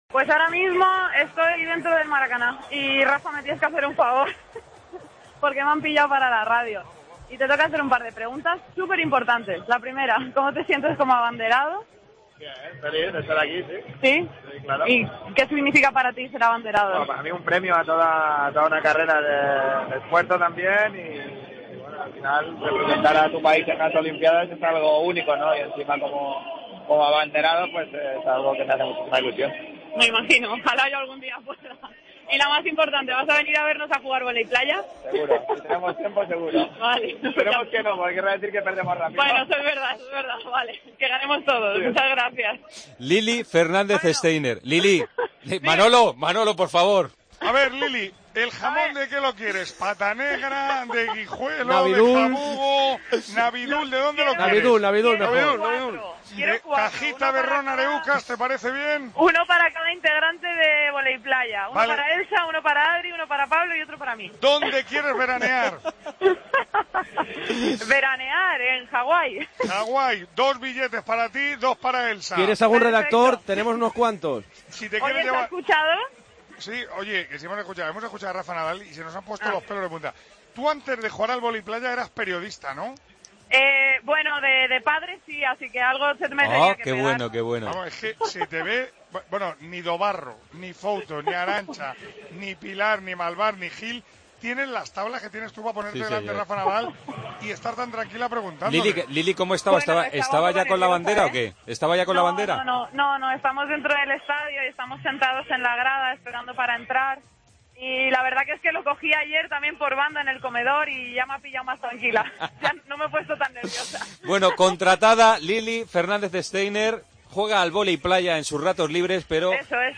Lili Fernández entrevista a Rafa Nadal durante la ceremonia inaugural
Redacción digital Madrid - Publicado el 28 dic 2016, 18:45 - Actualizado 17 mar 2023, 19:43 1 min lectura Descargar Facebook Twitter Whatsapp Telegram Enviar por email Copiar enlace La jugadora española de voley playa hace de periodista durante la ceremonia inaugural y consigue entrevistar al abanderado español, Rafa Nadal.